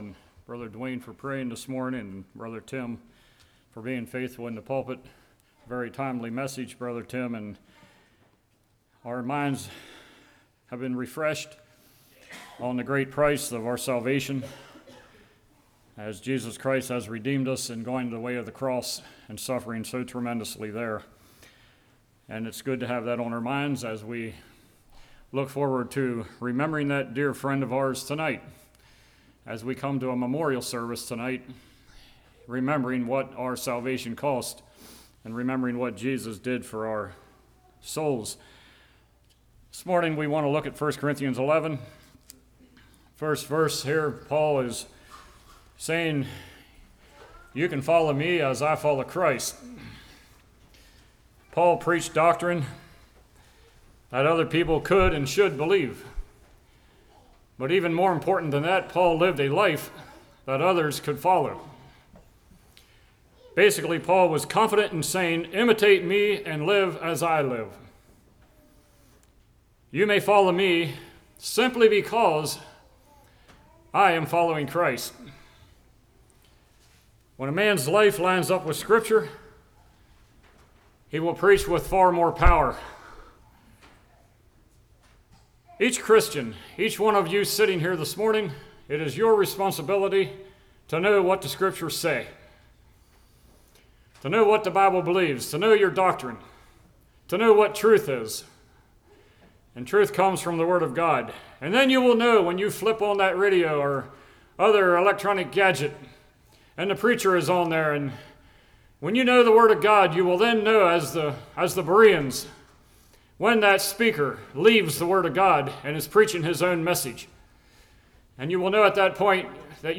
1 Corinthians 11:1-34 Service Type: Morning How Are We Living?